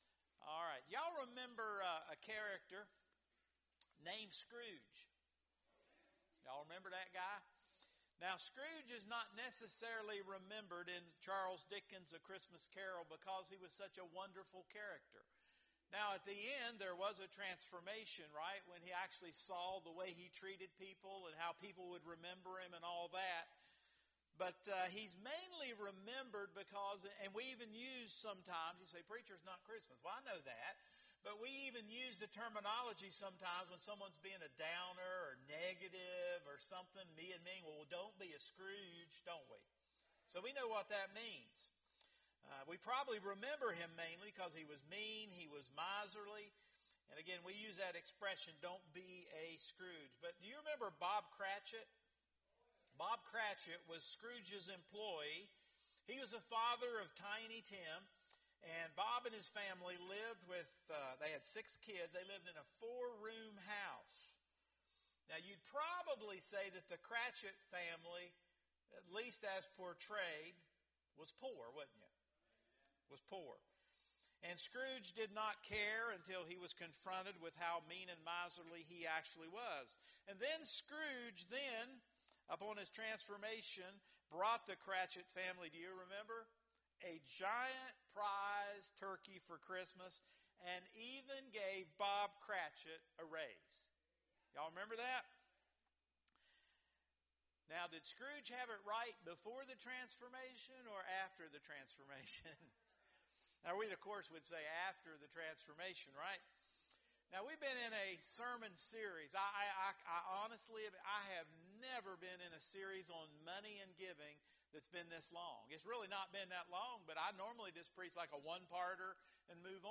Sermons | Oak Mound Evangelical Church